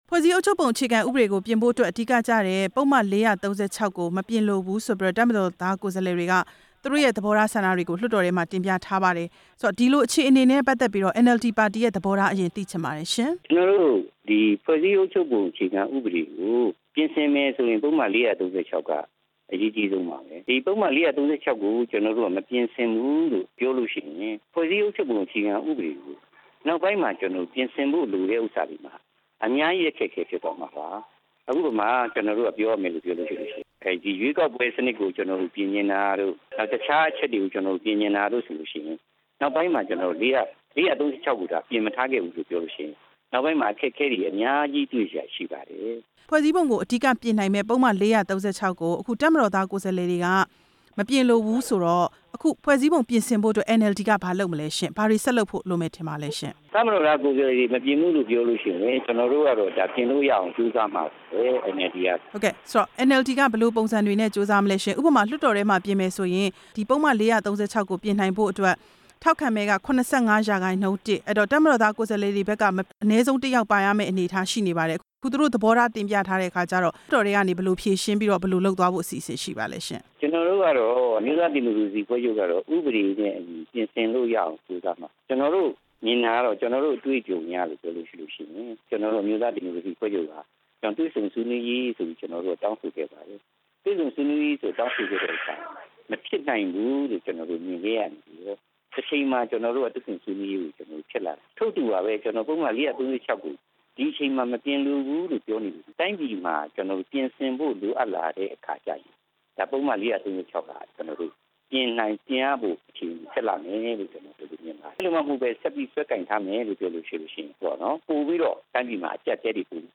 ဦးထွန်းထွန်းဟိန်နဲ့ မေးမြန်းချက်